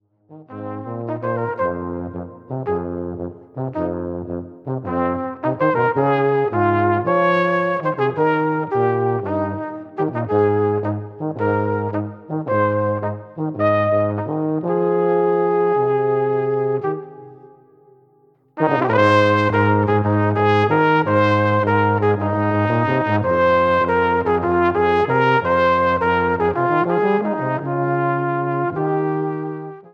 Gattung: für Flügelhorn und Posaune oder Bariton
Besetzung: VOLKSMUSIK Weisenbläser